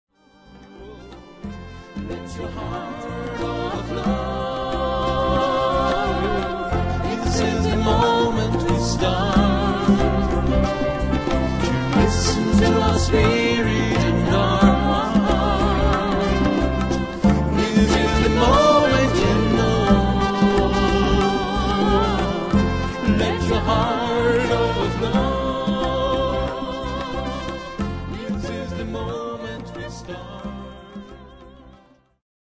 Ihr ausgezeichneter und vielseitiger Pianist